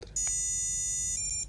Bekreftelseslyden er:"daaaa-di-di"